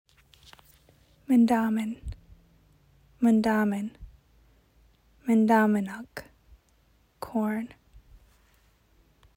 Anishinaabemowin pronuncation: "mun-daw-min (ug)"